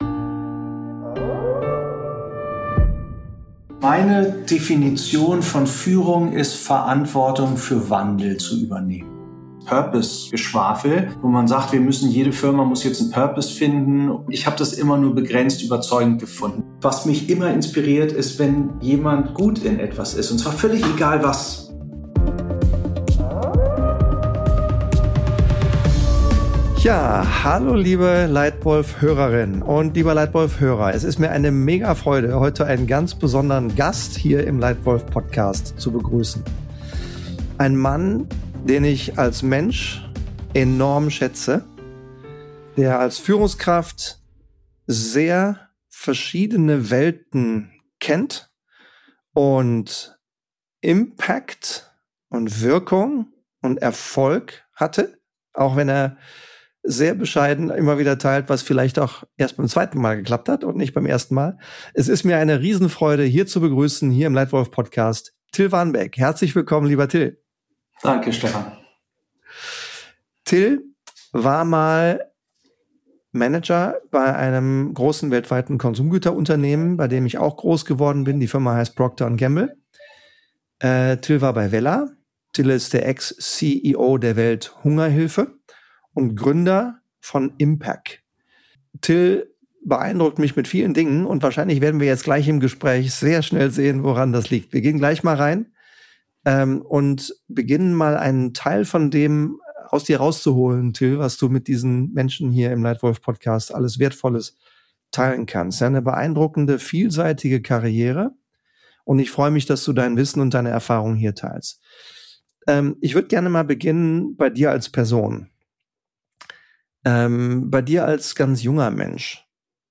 Ein Gespräch über Führung mit Perspektive, über Wirkung über Systemgrenzen hinweg und über die Frage, was wirklich zählt, wenn es darauf ankommt.